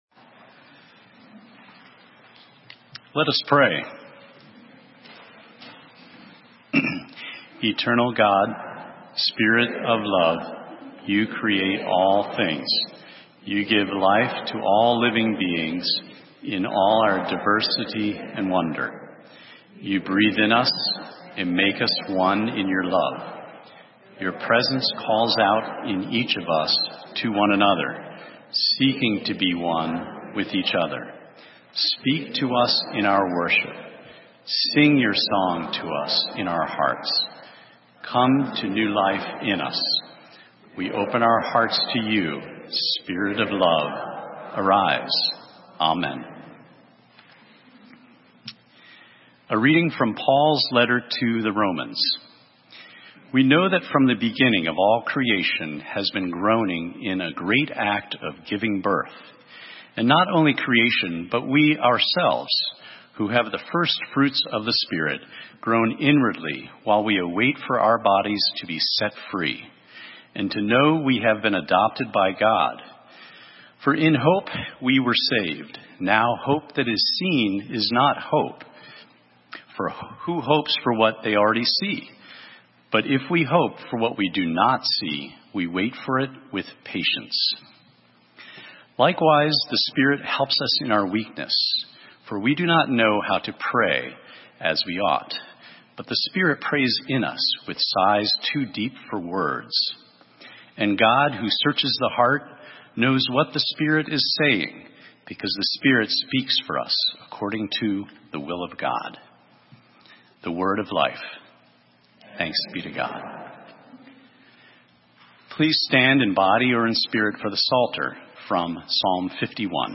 Sermon:Different languages - St. Matthews United Methodist Church